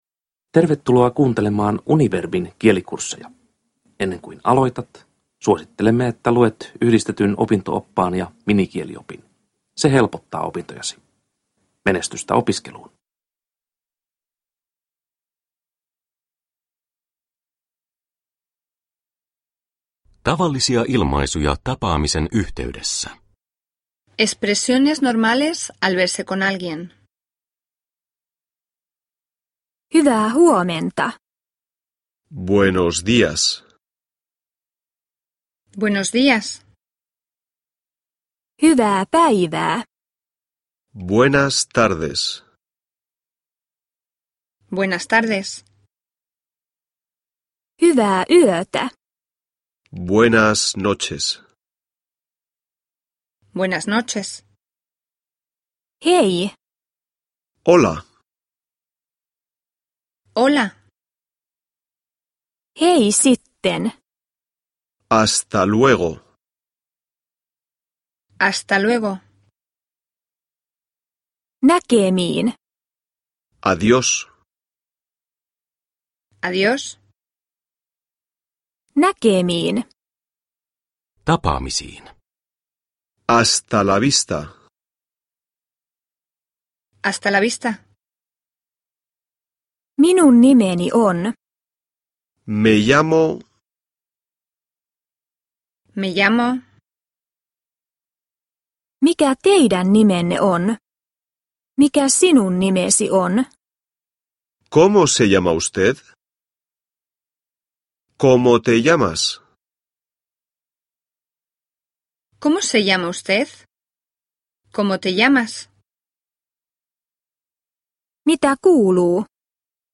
Peruskurssi on kaksikielinen. Lause kuullaan ensin suomeksi ja sitten kaksi kertaa vieraalla kielellä.
• Ljudbok